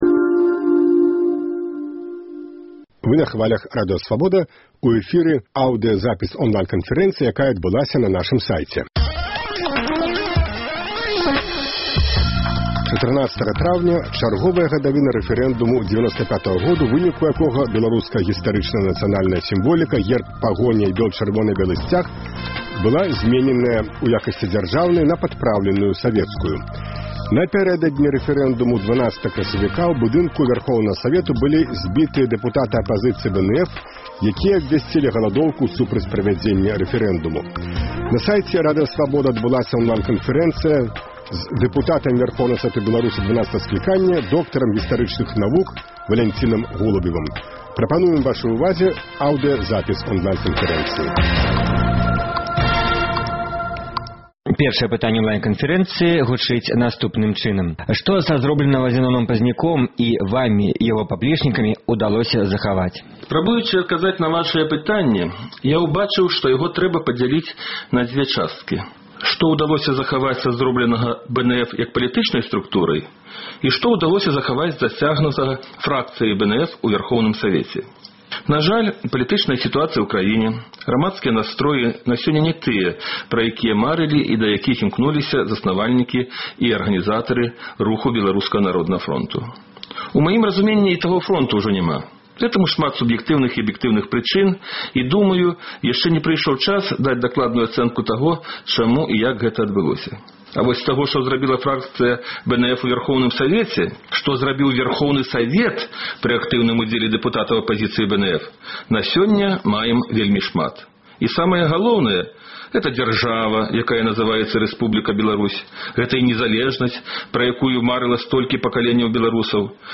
Онлайн-канфэрэнцыя з Валянцінам Голубевым